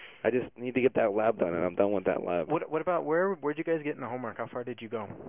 Supporting Information for Analyses of L2 English
1. "lab" (swift turn-taking): Dimension 3 low